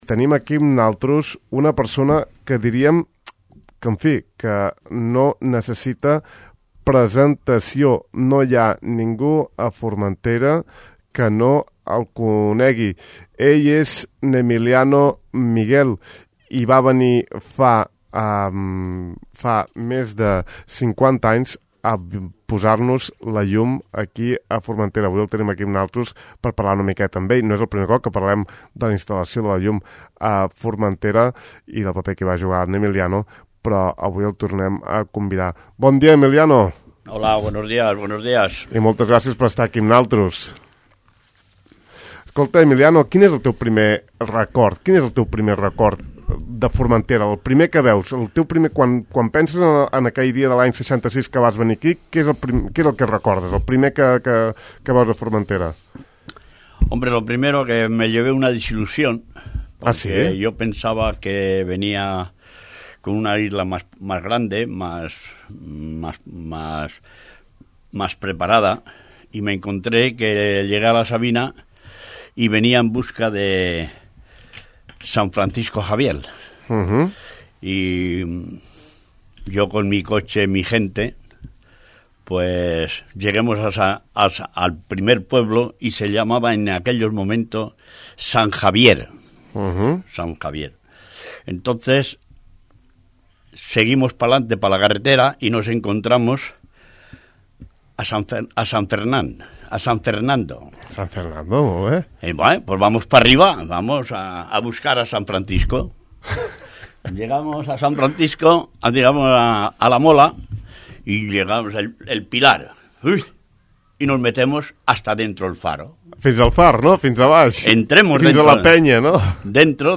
Podeu escoltar aquestes i altres anècdotes a la següent entrevista. https